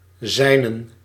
Ääntäminen
IPA : /ˈhɪz/ US : IPA : [hɪz]